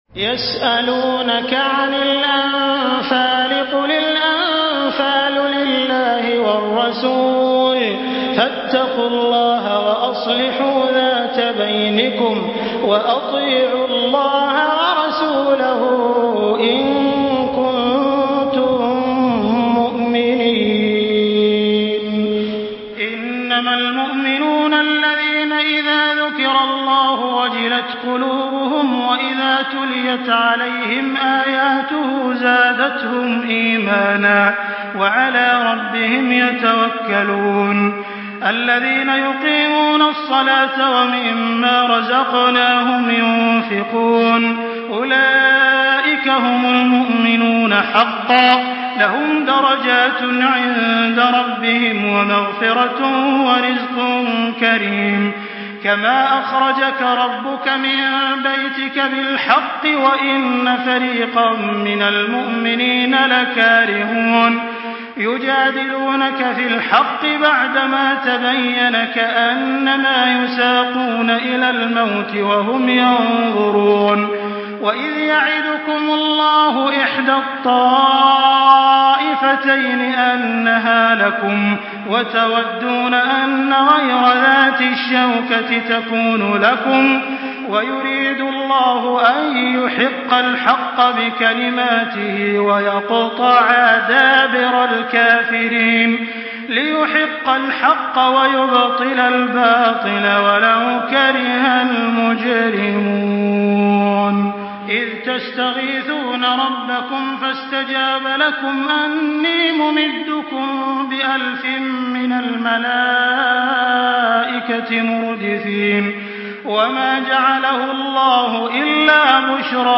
Surah আল-আনফাল MP3 by Makkah Taraweeh 1424 in Hafs An Asim narration.
Murattal Hafs An Asim